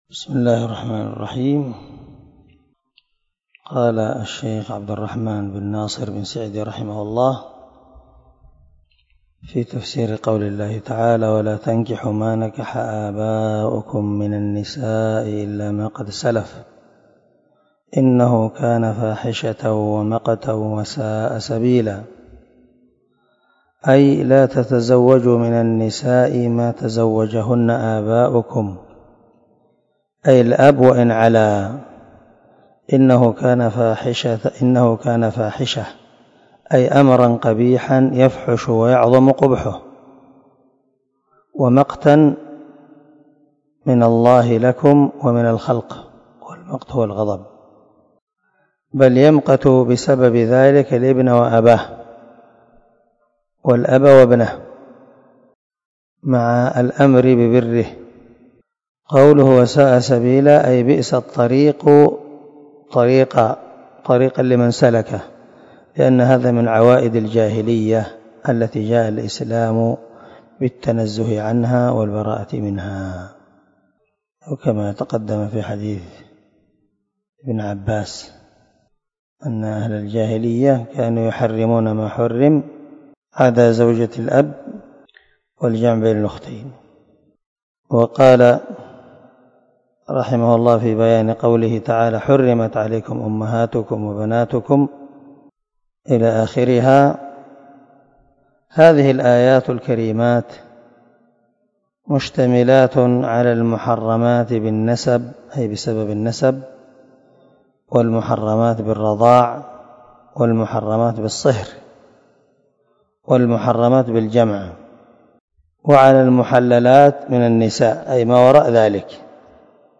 252الدرس 20 تابع تفسير آية ( 22 – 24 ) من سورة النساء من تفسير القران الكريم مع قراءة لتفسير السعدي
دار الحديث- المَحاوِلة- الصبيحة.